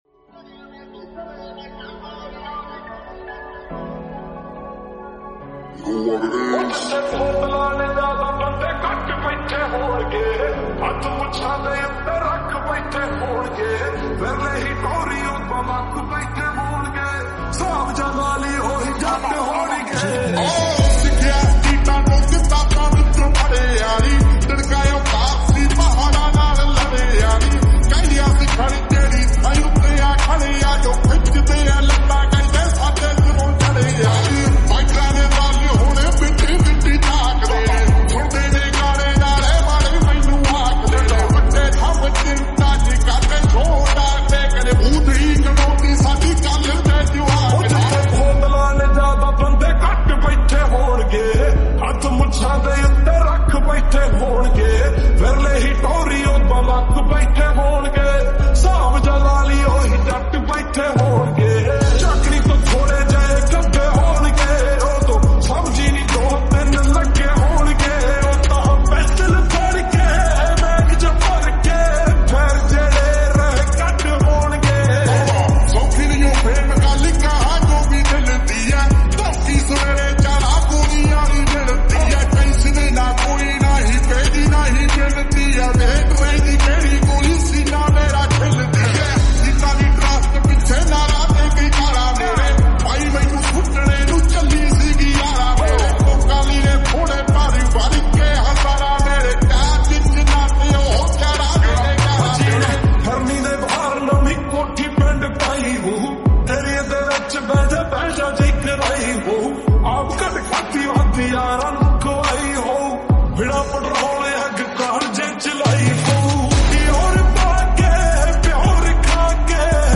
𝑴𝑬𝑮𝑨 𝑴𝑬𝑺𝑯𝑼𝑷 𝑺𝑶𝑵𝑮
(𝑺𝑶𝑳𝑽𝑬𝑫 𝑹𝑬𝑽𝑬𝑹𝑩 🥵🎧)